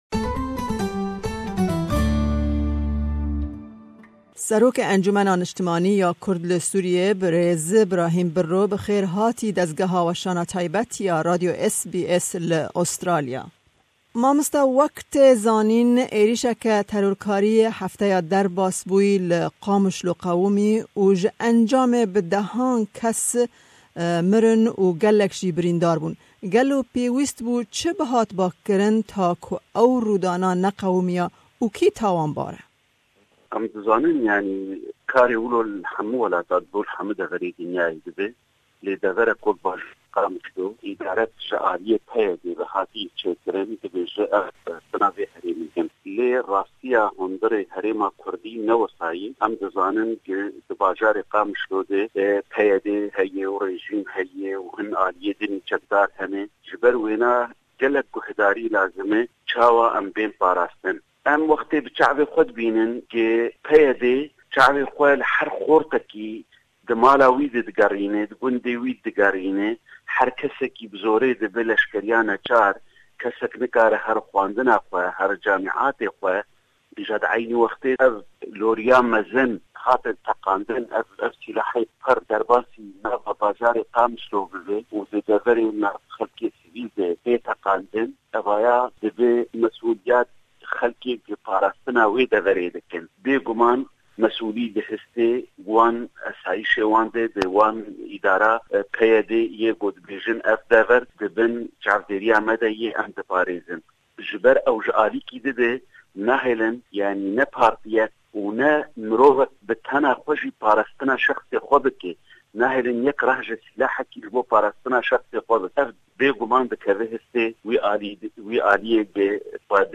Me hevpeyvînek bi serokê ENKS Ibrahim Biro sebaret bi teqîna ku vê dawiyê li Qamishlo/Qamishlî cî girtî pêk anî ku di encamê bi dehan mirin û jimareke mezin jî brîndar bûn.